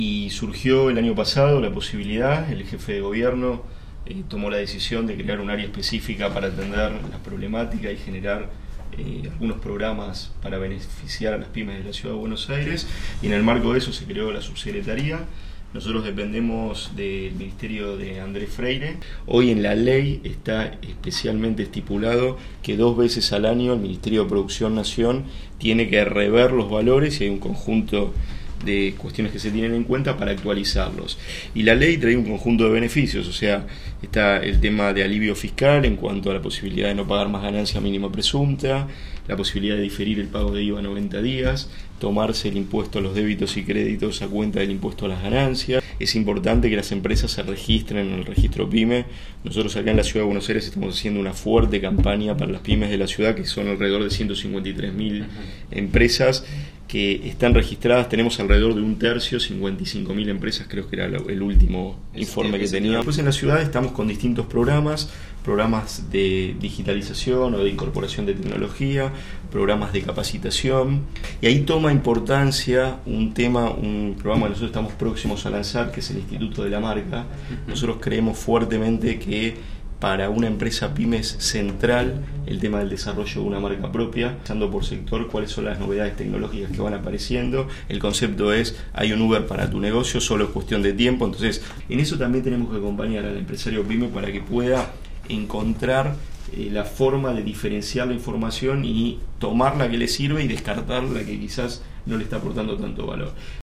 Así lo afirmó Martín Trubycz, Subsecretario de BA PyME, en una entrevista con la radio de la Cámara Argentina de la Construcción (CAMARCO).